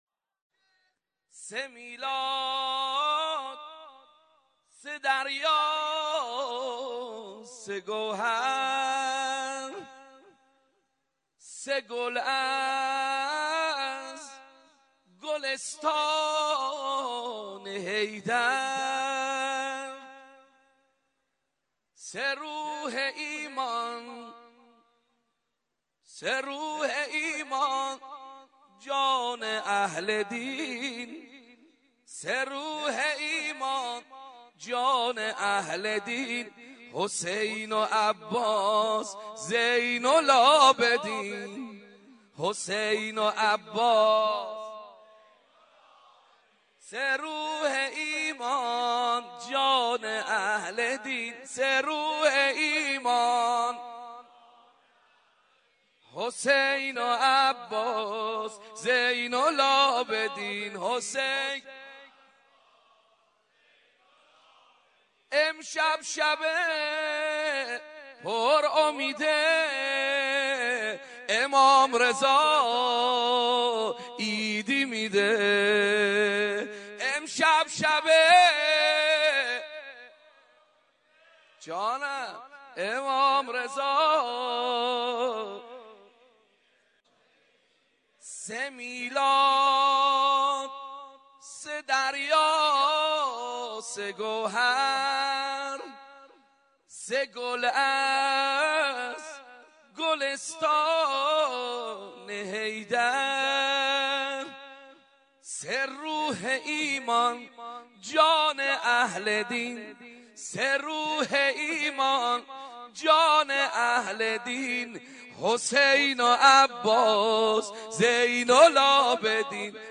دانلود سرود بمناسبت اعیاد شعبانیه